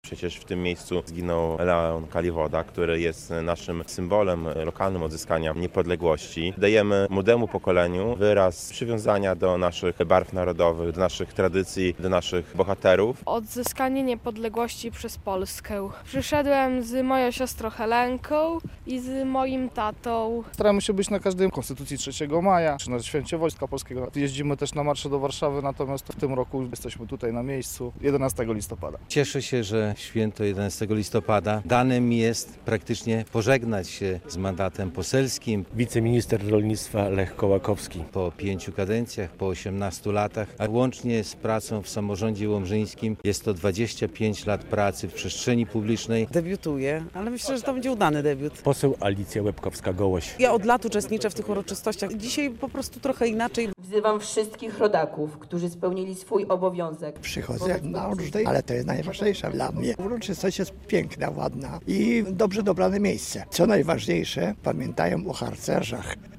Obchody Narodowego Święta Niepodległości w Łomży i okolicach - relacja